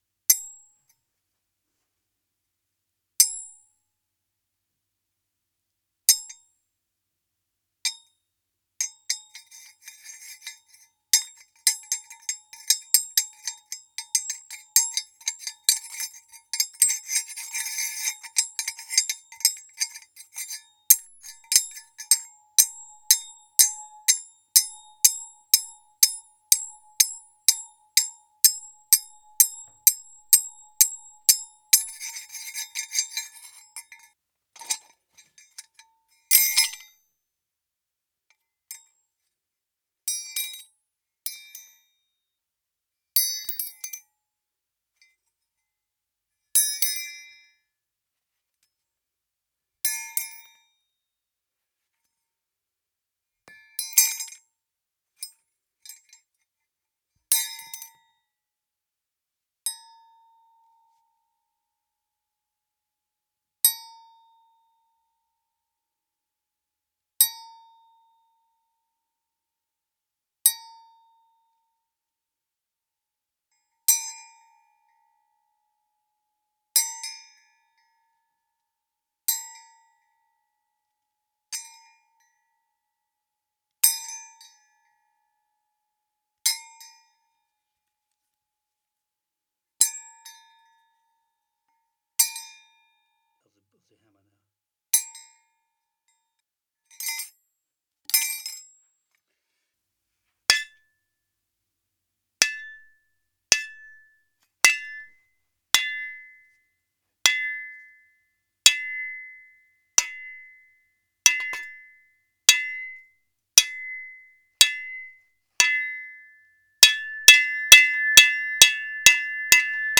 METAL SFX & FOLEY, Metal dings with reverb